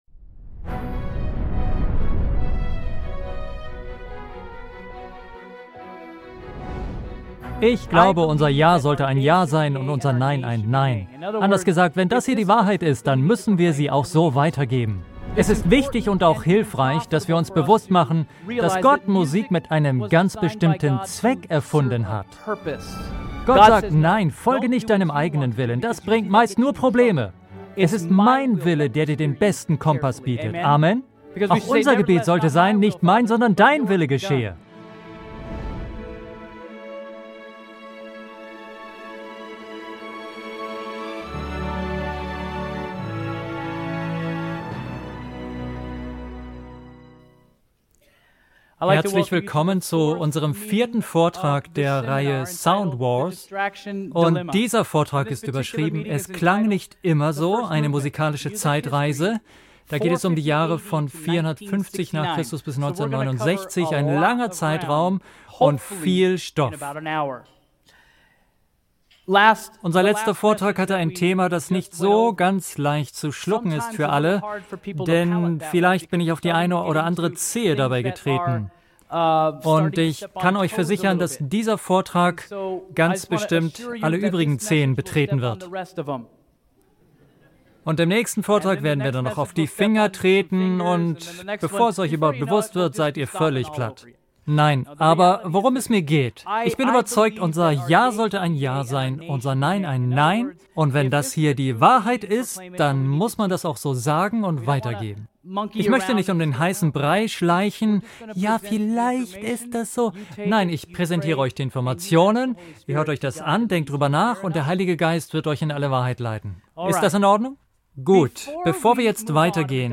Dann bist du bei diesem Seminar genau richtig.